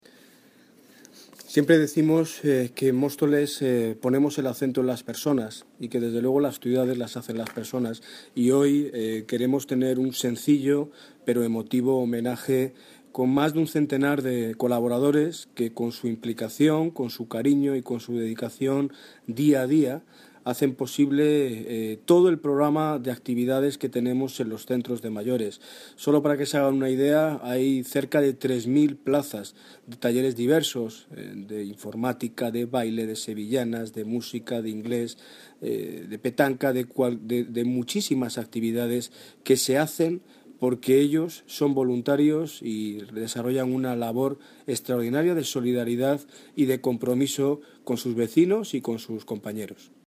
Audio - Daniel Ortiz (Alcalde de Mótoles) Homenaje Colaboradores Mayores